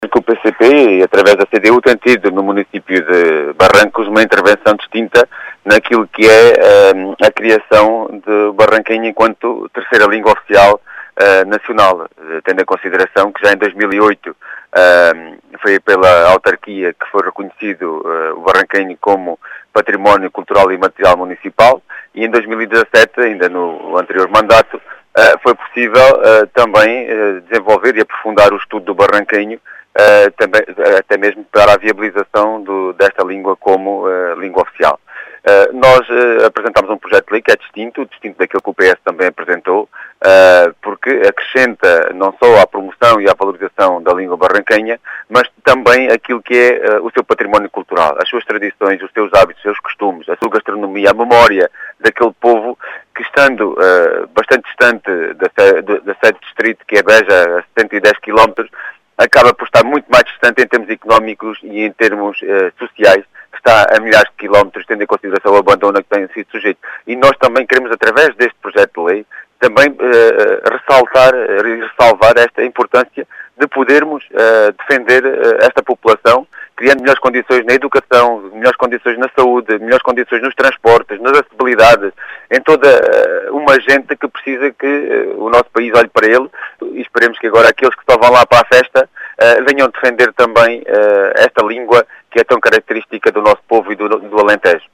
As explicações são do deputado do PCP, João Dias.